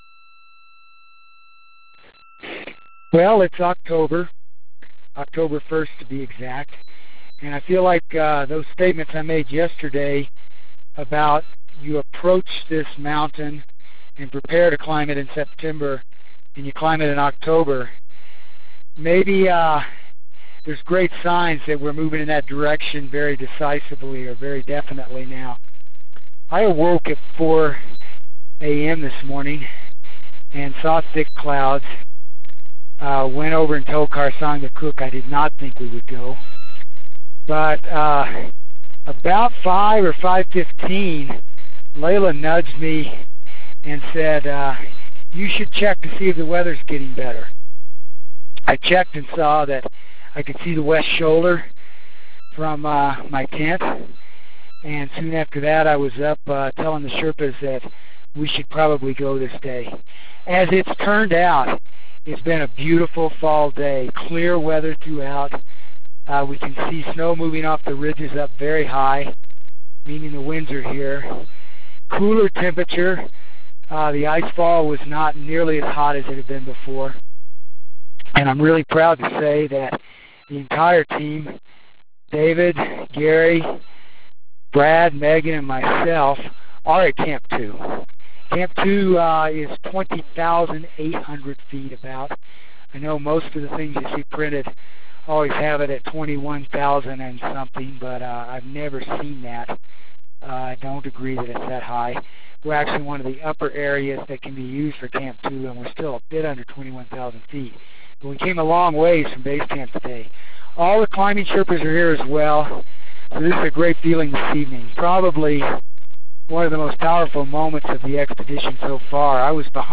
October 1 - Ski route looks great from Camp 2